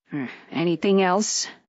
Add Juni Mission Voice Files